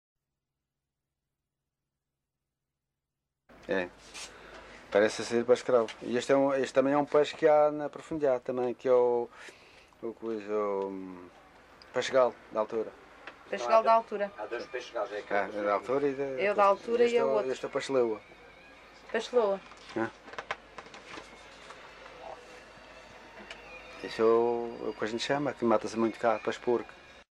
LocalidadeCaniçal (Machico, Funchal)